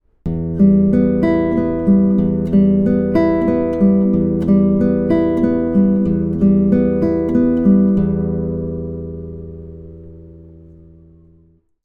P-i-m-a-m-i Arpeggio Pattern | Practice on the open strings first.